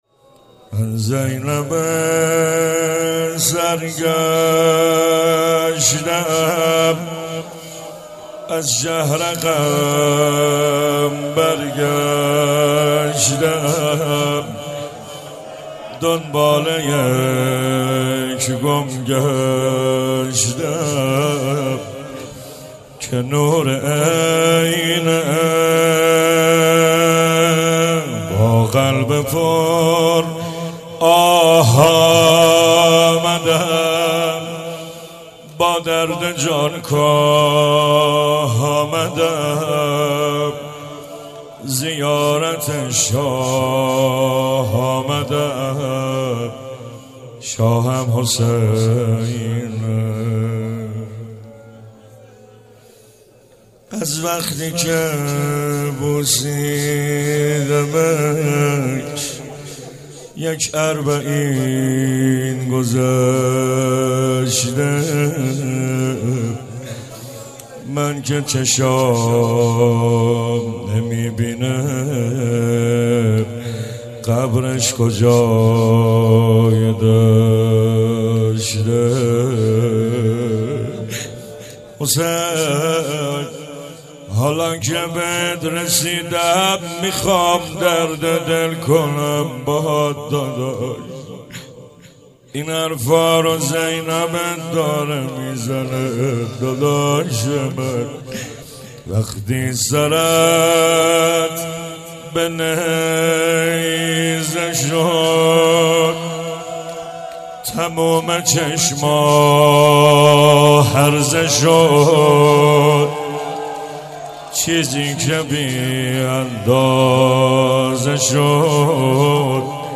مناسبت : اربعین حسینی
قالب : روضه